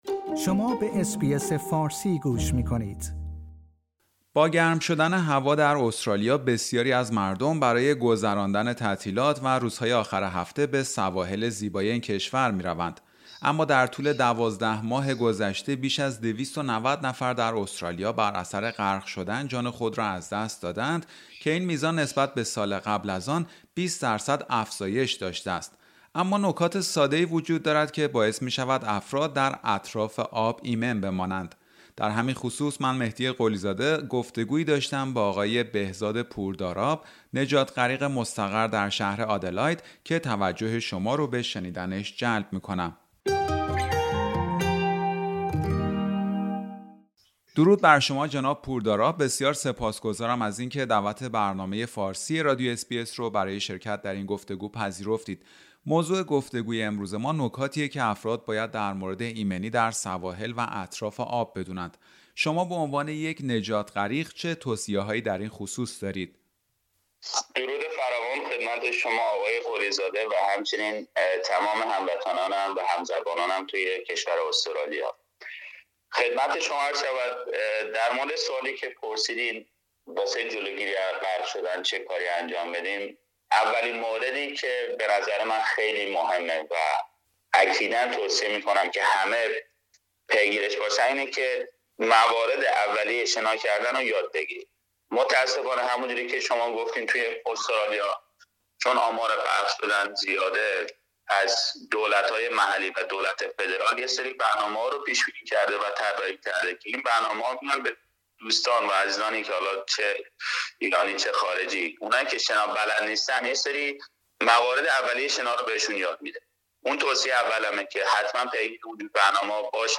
برنامه فارسی رادیو اس بی اس گفتگویی داشته